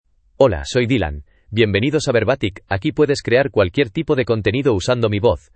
MaleSpanish (Spain)
DylanMale Spanish AI voice
Dylan is a male AI voice for Spanish (Spain).
Voice sample
Dylan delivers clear pronunciation with authentic Spain Spanish intonation, making your content sound professionally produced.